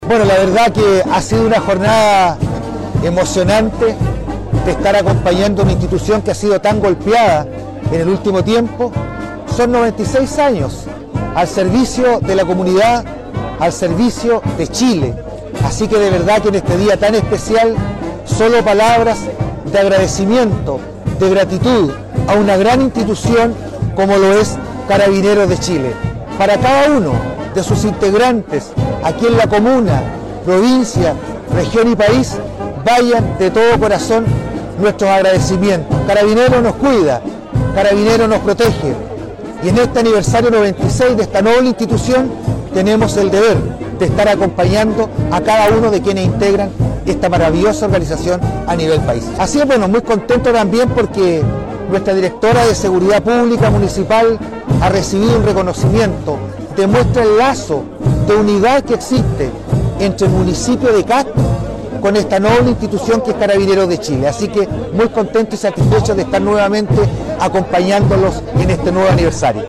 Alcalde Vera saludó a Carabineros en su aniversario 96